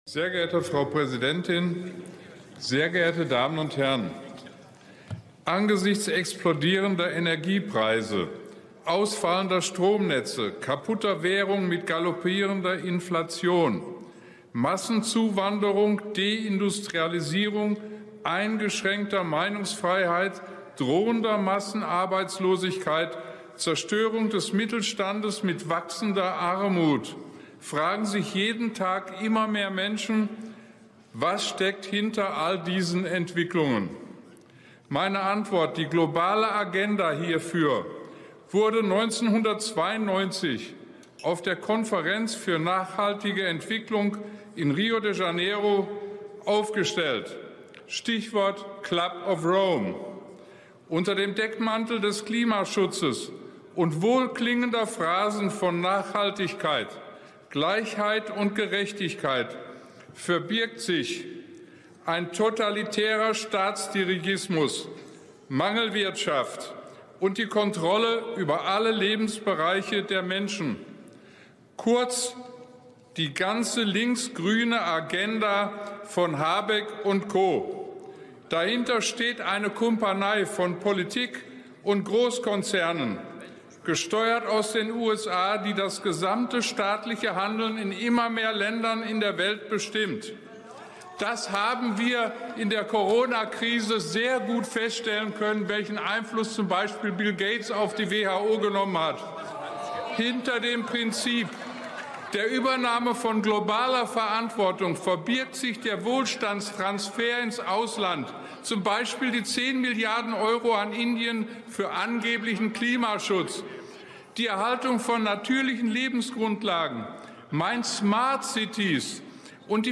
Der fraktionslose Abgeordnete Robert Farle sprach vor kurzem im deutschen Bundestag und rechnete dabei gnadenlos mit der Politik der Bundesregierung und dem geplanten "Great Reset" ab. Seine Redezeit nutzte er, um die "Agenda 2030" scharf zu kritisieren.